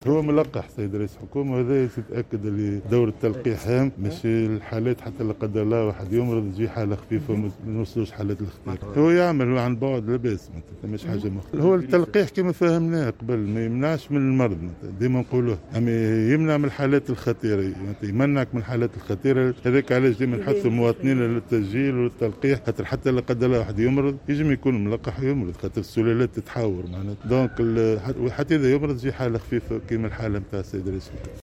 قال وزير الصحة فوزي المهدي اليوم السبت 26 جوان 2021، ان التلقيح لا يمنع المرض بالكوفيد لكنه يمنع تعكر الحالة الصحية في تعليقه على اصابة رئيس الحكومة هشام المشيشي بفيروس كورونا رغم تلقيه التلقيح في جرعتين.